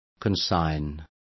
Also find out how entregar is pronounced correctly.